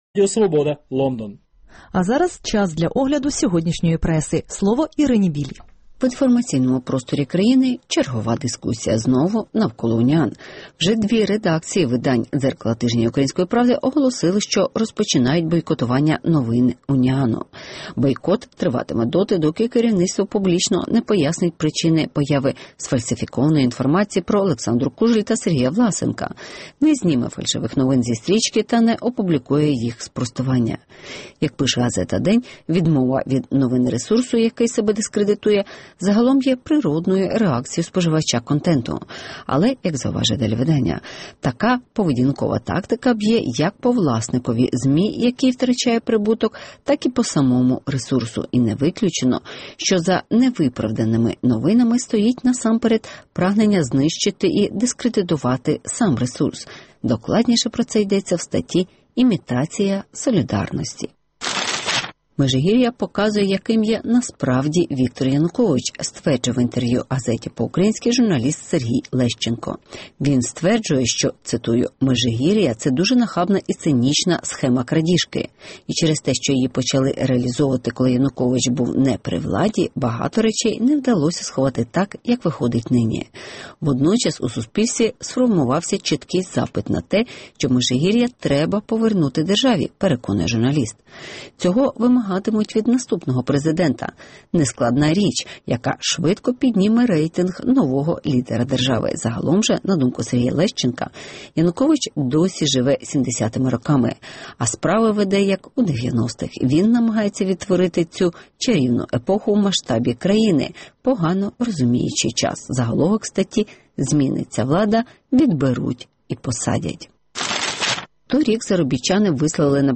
Хто відбере Межигір’я у Януковича? (Огляд преси)